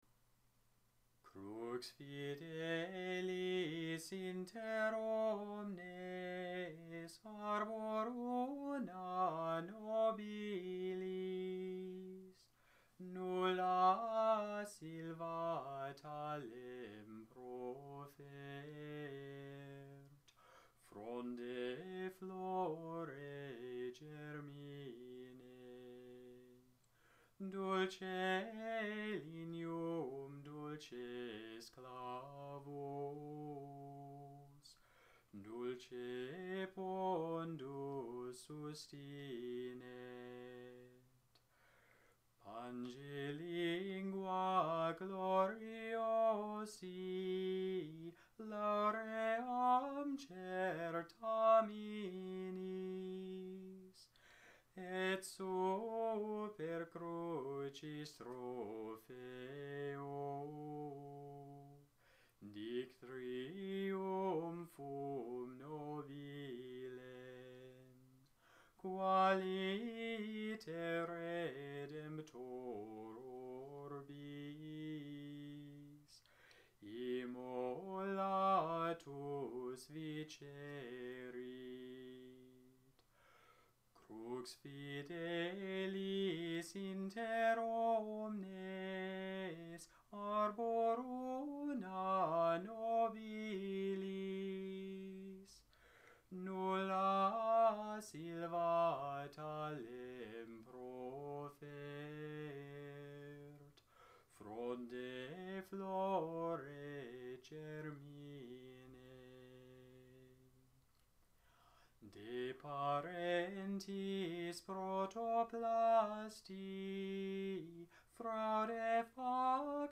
Gregorian chant audios